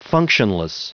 Prononciation du mot functionless en anglais (fichier audio)
Prononciation du mot : functionless